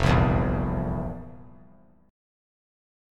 FM7sus4 Chord
Listen to FM7sus4 strummed